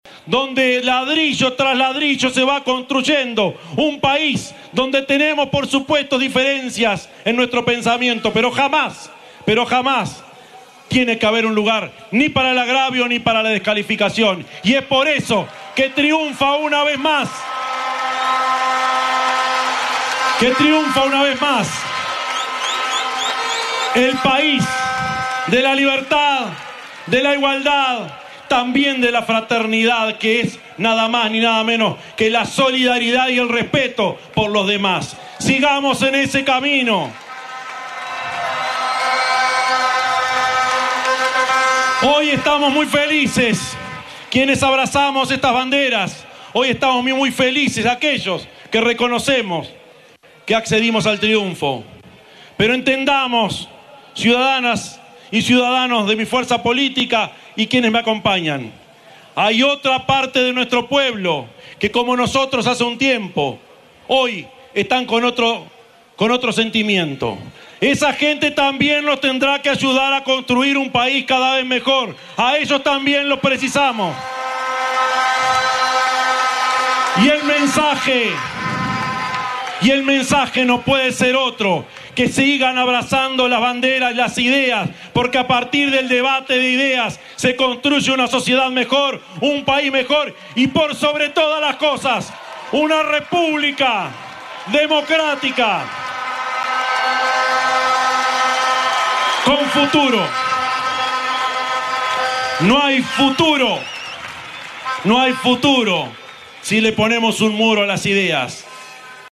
El presidente electo Yamandú Orsi en su discurso ante la militancia dijo que “triunfa una vez más el país de la libertad y de la igualdad».
Orsi-discurso.mp3